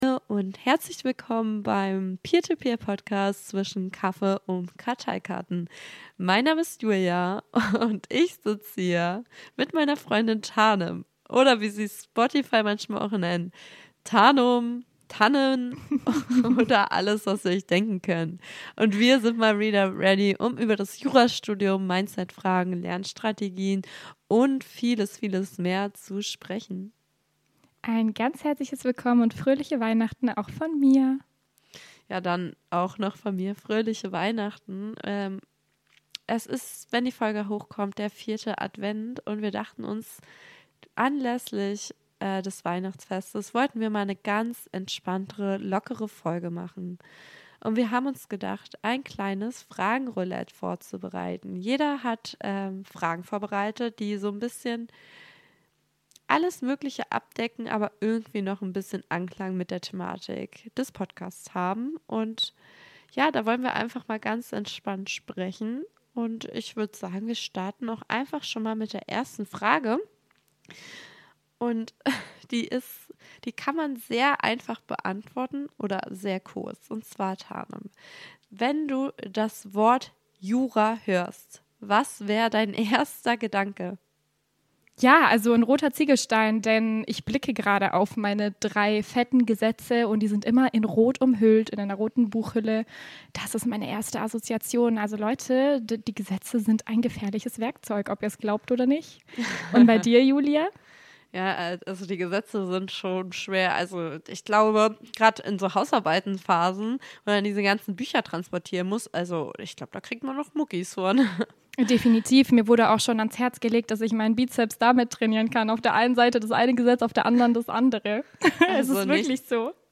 Statt Lernstrategien und Lernplänen gibt es einen locker flockigen Talk rund um Jura, den Podcast und alles dazwischen.
Diese Folge ist unser kleines Weihnachtsspecial: persönlich, entspannt und mit einem Augenzwinkern.